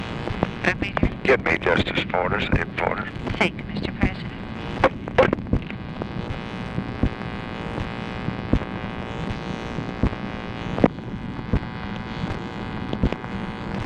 LBJ ASKS TELEPHONE OPERATOR TO PLACE CALL TO ABE FORTAS
Conversation with TELEPHONE OPERATOR